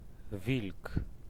Ääntäminen
Ääntäminen France: IPA: [lu]